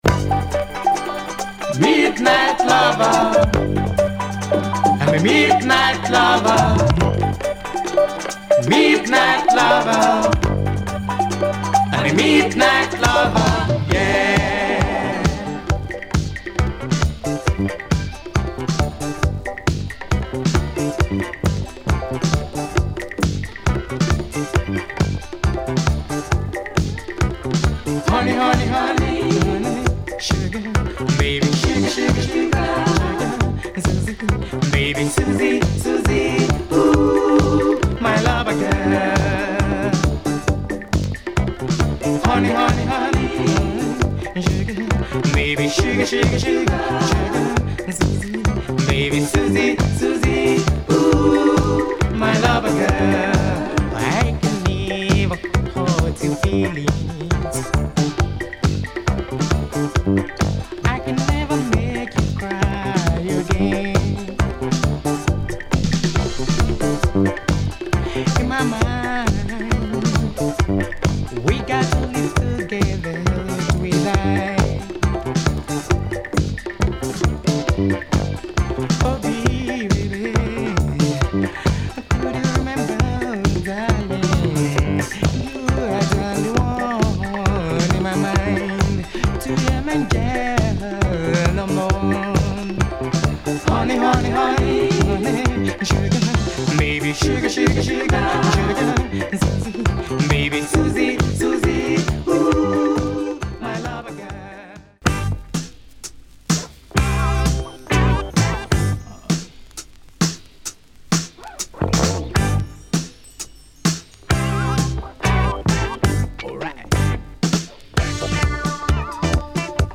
Nigerian boogie funk and disco